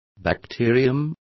Complete with pronunciation of the translation of bacteria.